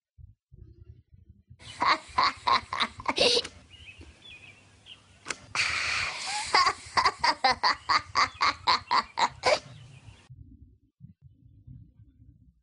Voici pour finir un extrait du rire diabolique de Bart, qui résume très bien l'identité du personnage (à écouter sans modération):
bart enregistre son rire